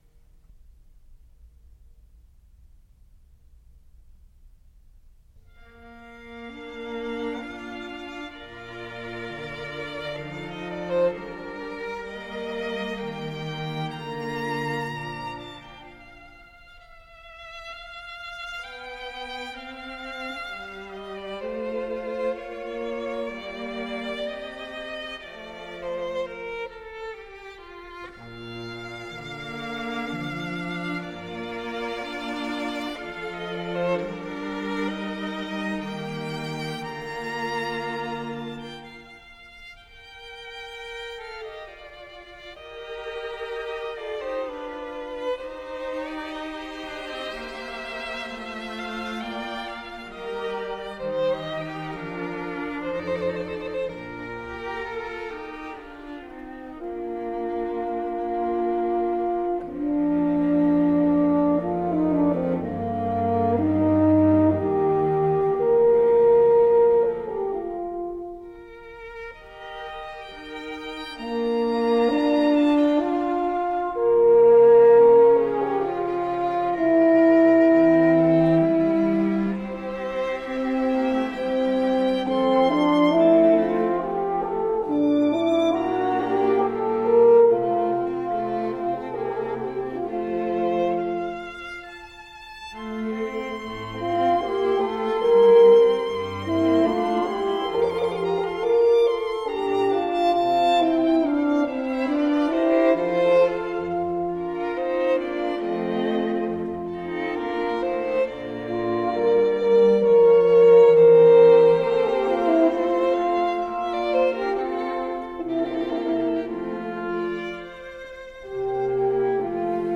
Andante - Horn Quintet